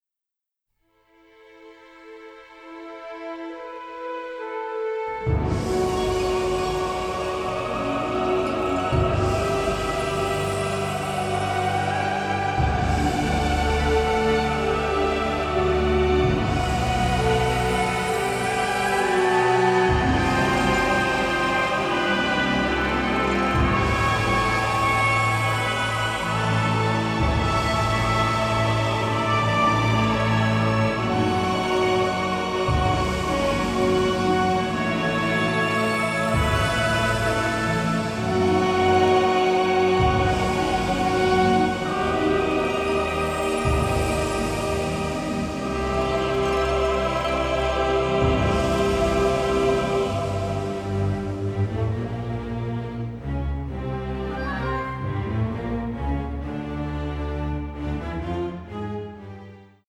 adventure score
Middle Eastern flavor and religious splendor